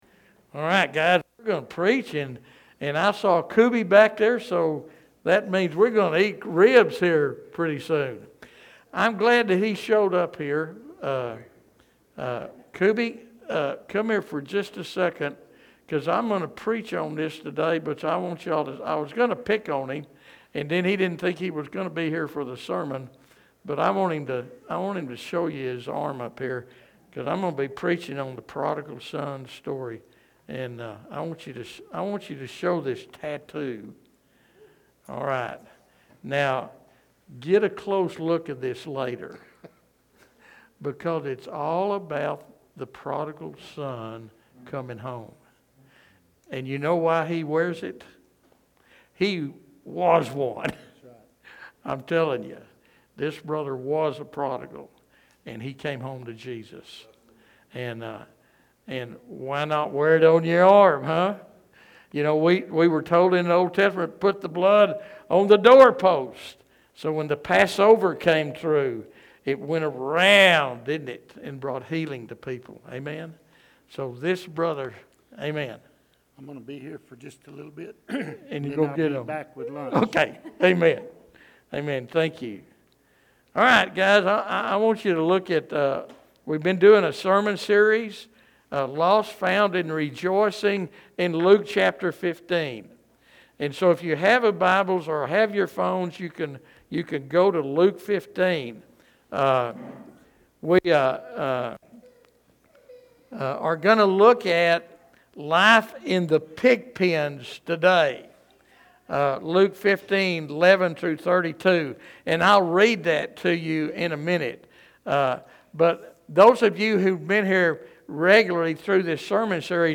This week's sermon explores the complete story of the Prodigal Son - not just the rebellious younger son, but also the bitter elder brother and the extravagantly loving father.